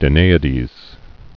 (də-nāĭ-dēz)